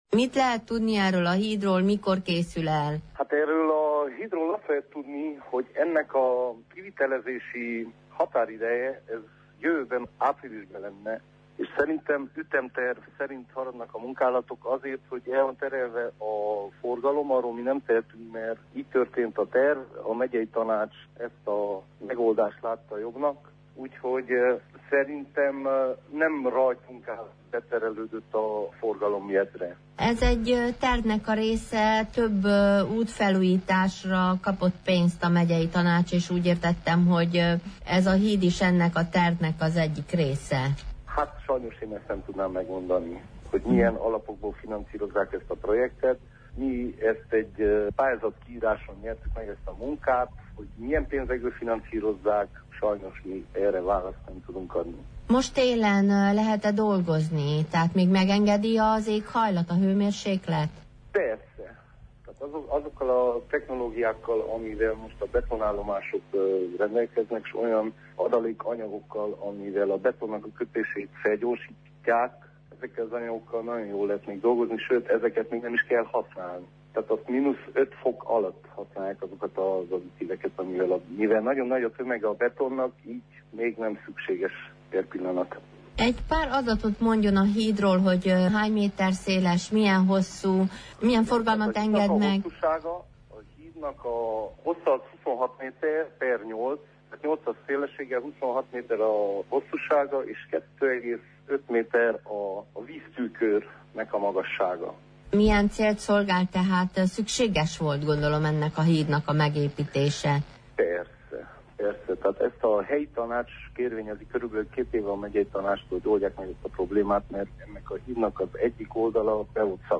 majd az alpolgármestert, Bányai Istvánt szólaltatjuk meg.